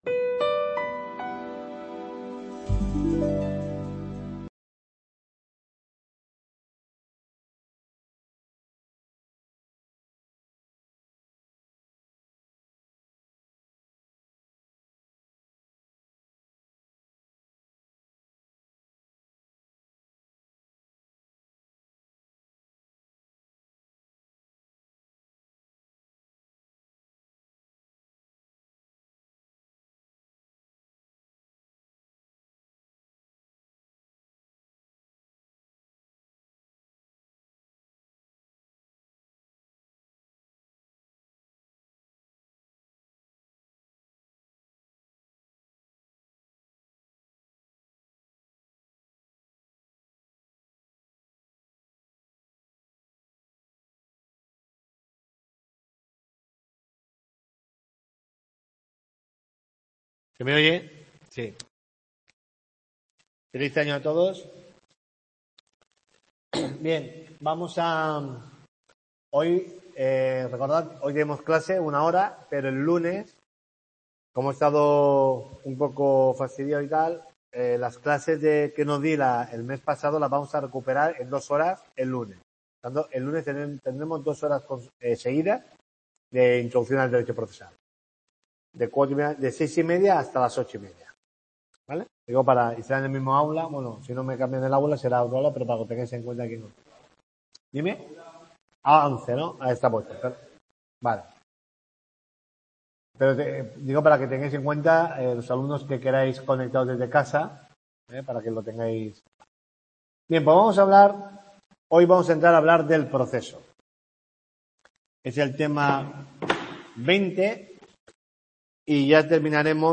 TUTORIA 10